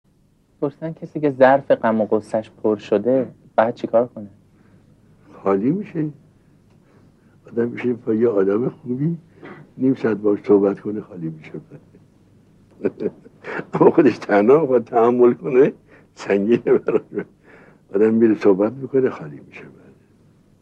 به گزارش خبرگزاری حوزه، مرحوم آیت الله عزیزالله خوشوقت از اساتید اخلاق حوزه در یکی از دروس اخلاق به پرسش و پاسخی پیرامون «راهکار سبک شدن در غم و اندوه» پرداختند که متن آن بدین شرح است: